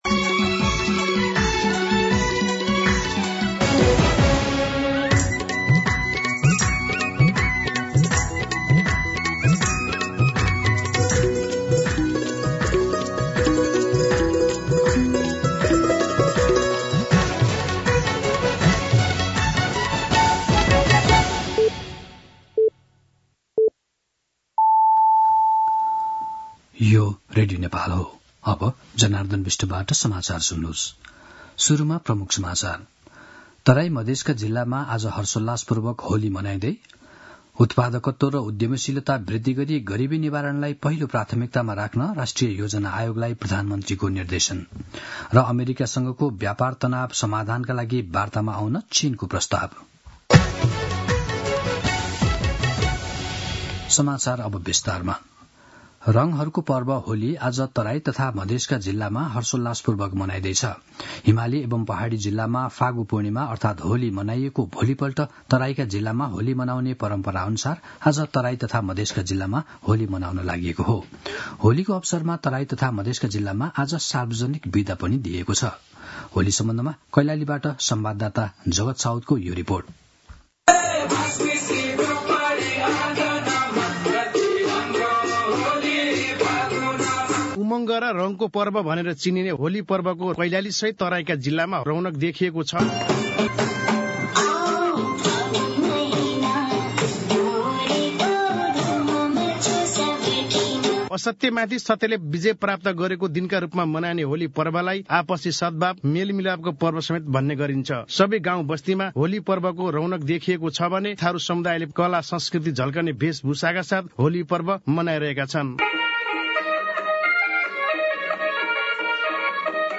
दिउँसो ३ बजेको नेपाली समाचार : १ चैत , २०८१